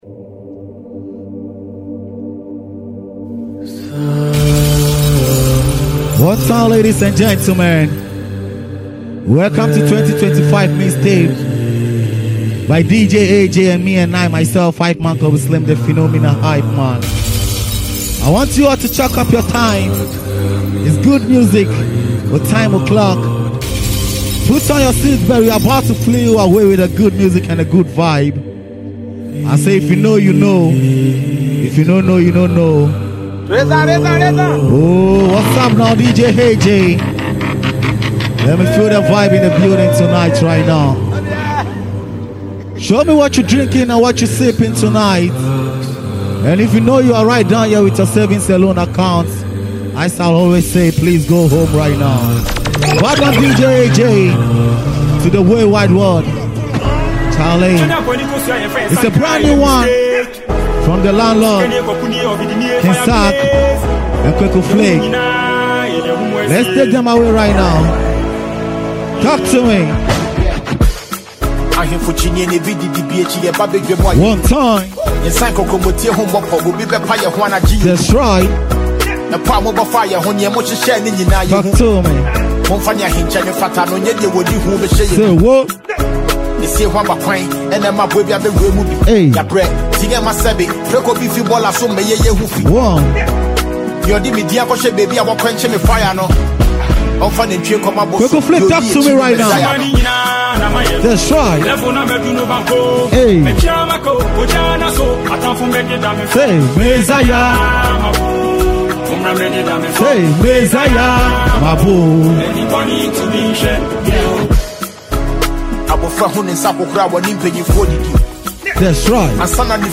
new mix